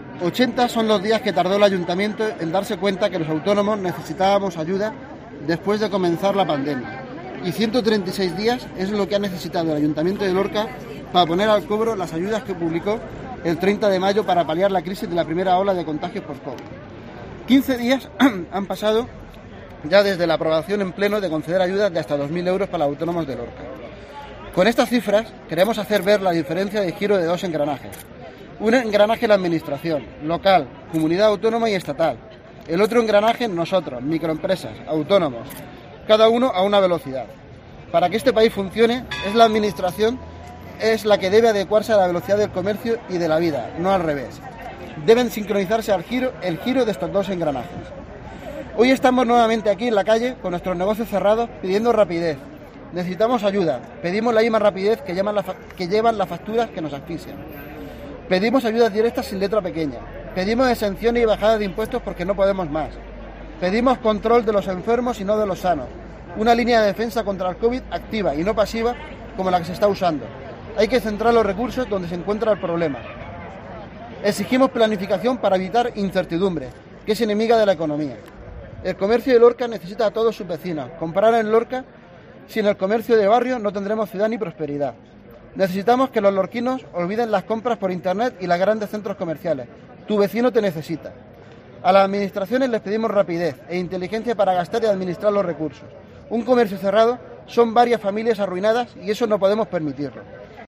Comunicado Autónomos de Lorca en Puerta del Ayuntamiento